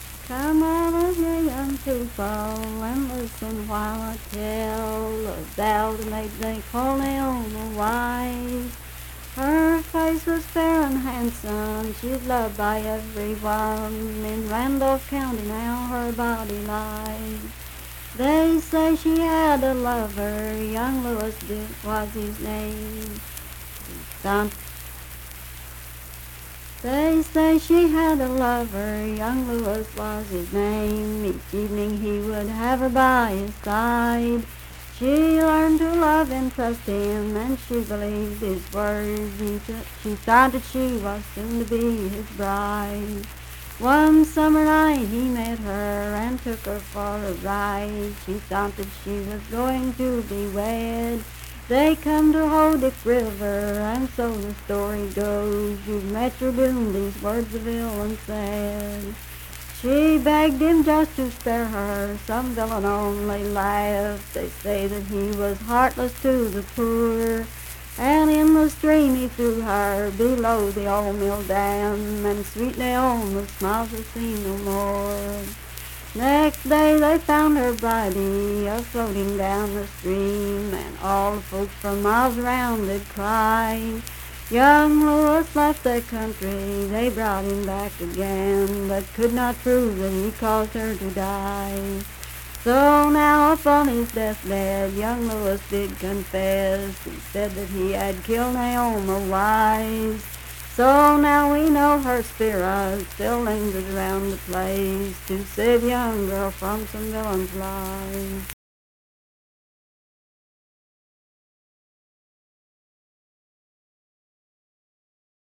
Unaccompanied vocal music
Verse-refrain 6d(4).
Voice (sung)
Sutton (W. Va.), Braxton County (W. Va.)